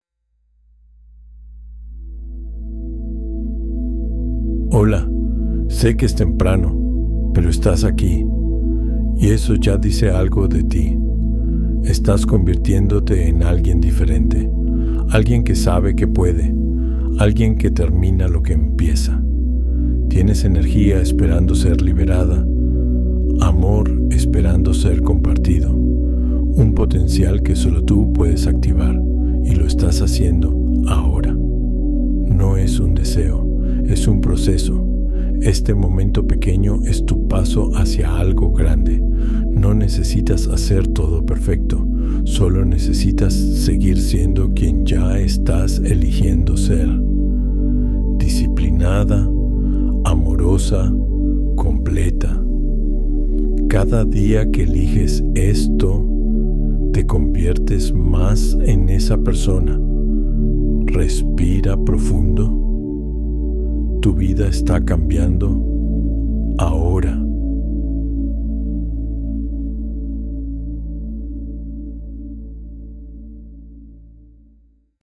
alarma_masculino_V2.mp3